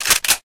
close.ogg